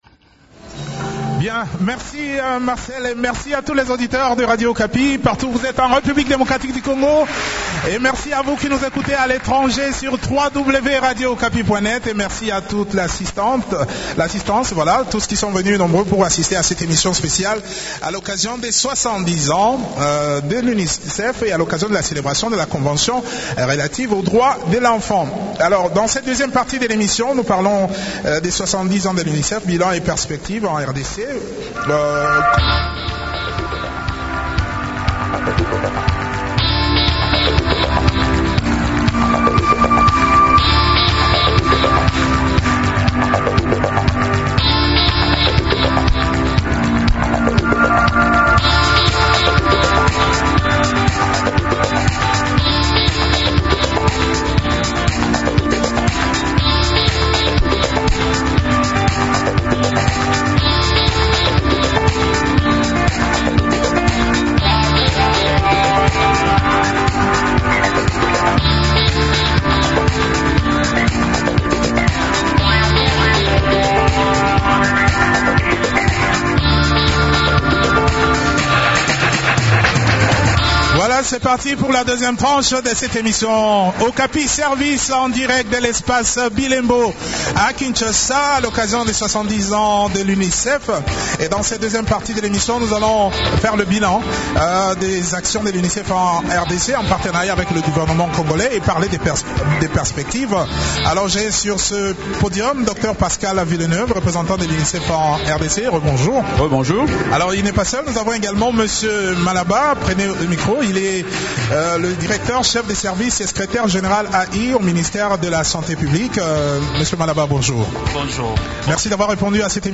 Cette émission grand-public est organisée à l’espace BILEMBO à Kinshasa dans le cadre de la célébration des 70 ans du Fonds des Nations unies pour l’enfance (Unicef).